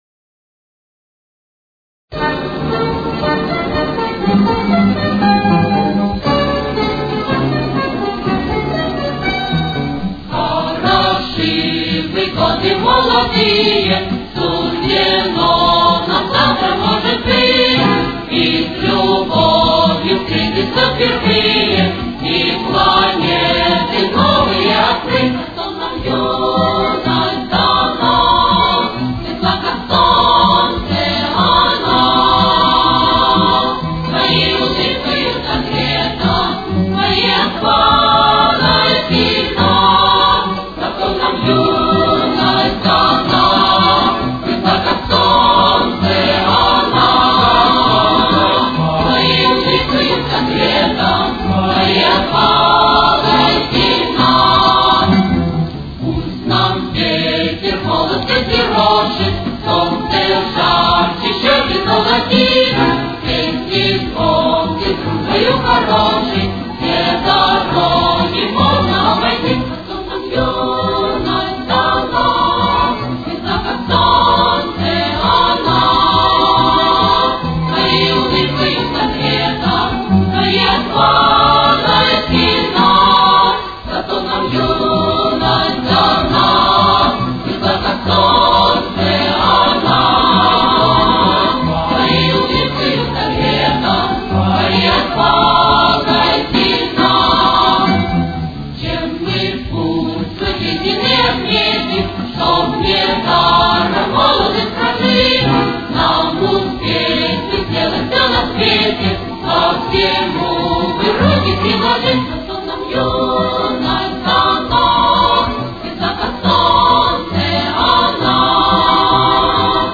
Темп: 124.